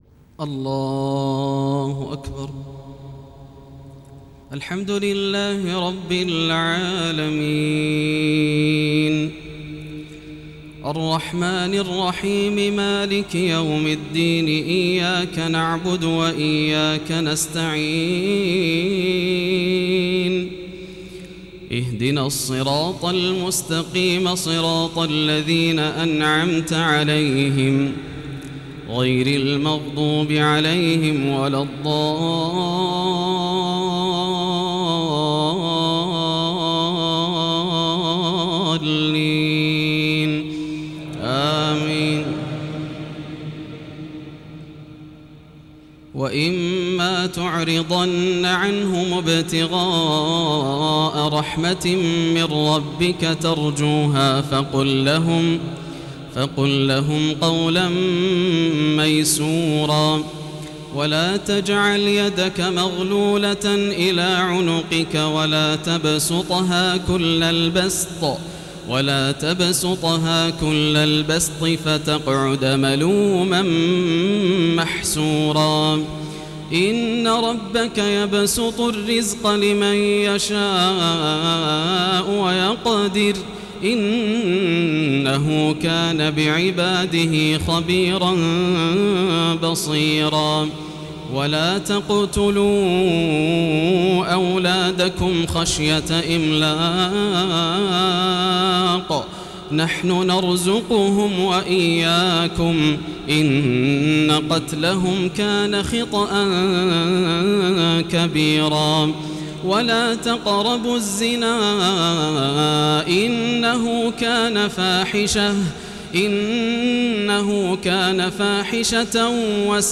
تراويح ليلة 11 رمضان 1432هـ من مسجد جابر العلي في دولة الكويت > الليالي الكاملة > رمضان 1432 هـ > التراويح - تلاوات ياسر الدوسري